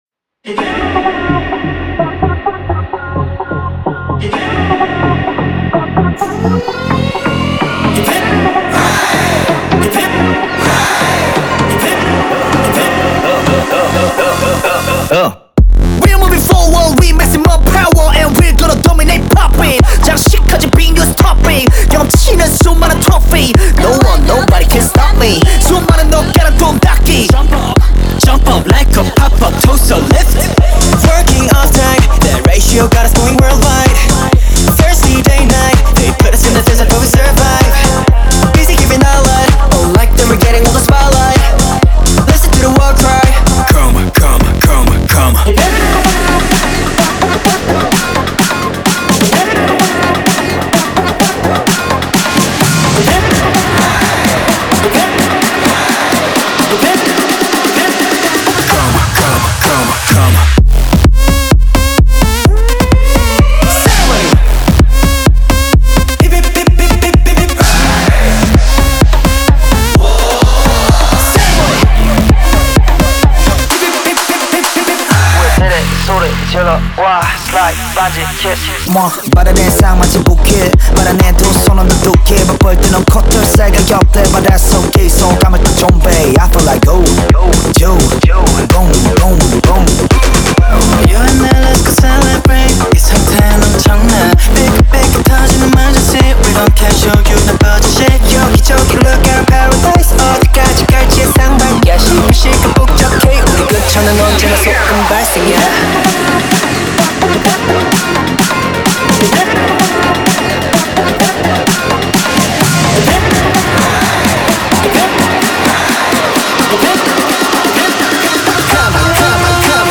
Корейские песни